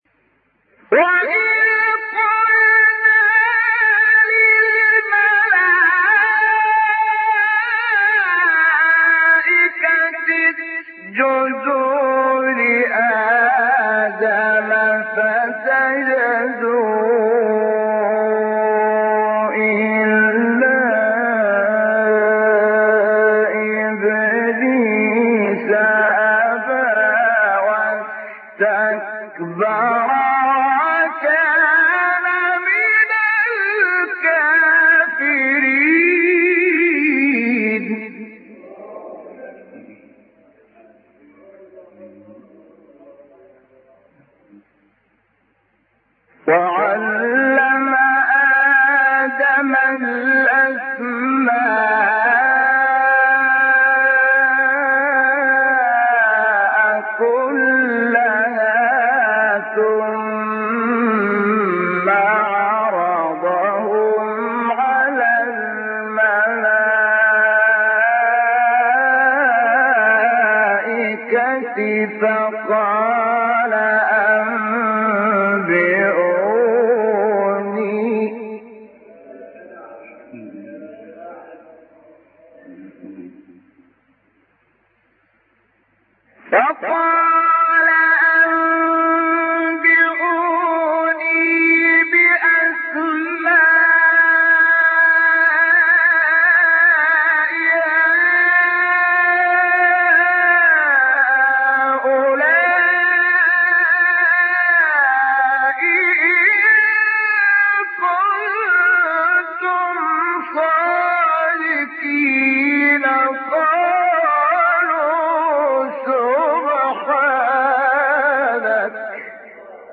سوره : بقره آیه: 34-35 استاد : شحات محمد انور مقام : سه گاه قبلی بعدی